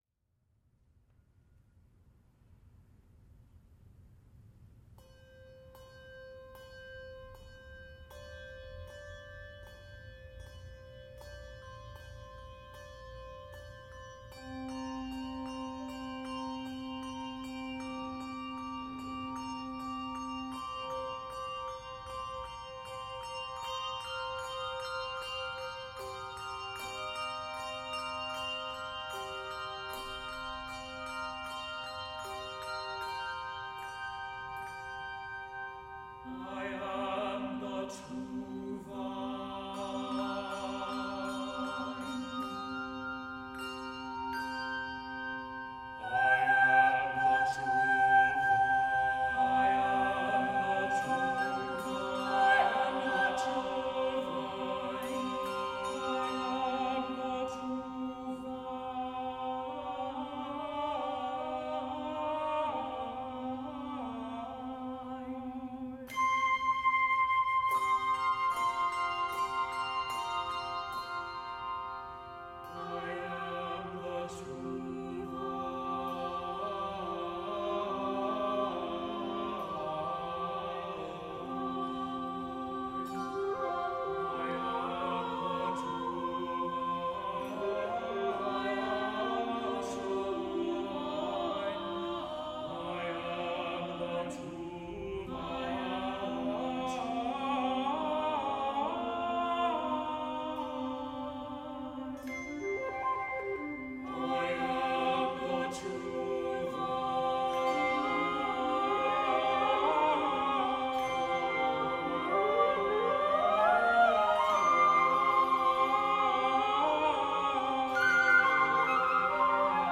Anthem for Solo SATB Quartet, flute, Bell Choir and Organ (or piano)
This is a meditative piece that’s kind of the quiet before the storm of the Passion music for Good Friday.
The bells on this recording come from the storied Bell Choir dynasty of the Union Church of Hinsdale.